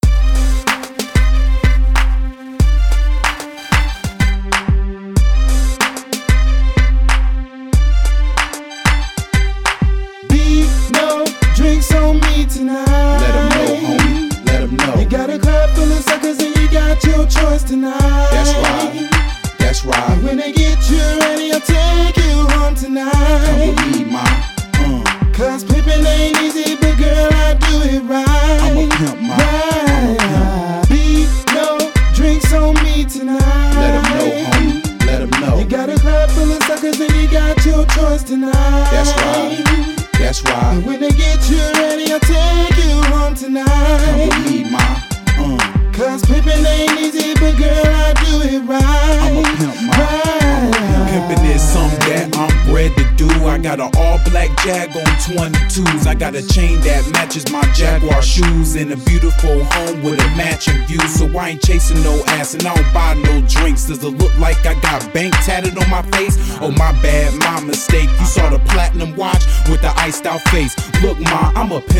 Hip Hop Reviews